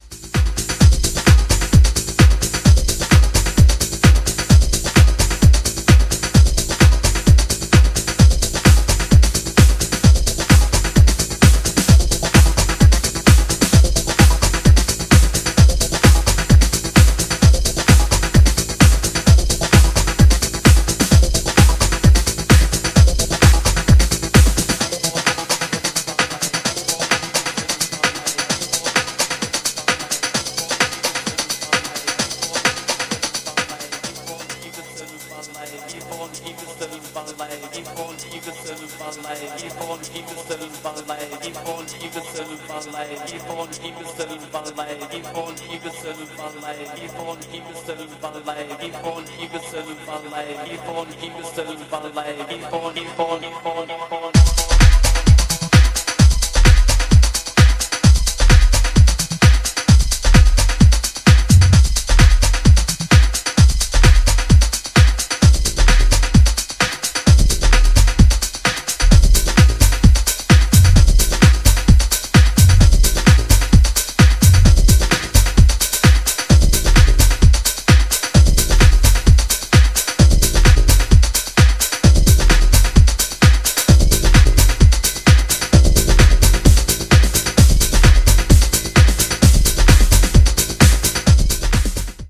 程よくアシッド/エフェクト/ヴォイス・サンプルを配し展開をつけながら、グルーヴィーなミニマル・ハウスを披露。